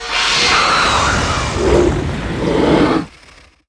c_alien_hi.wav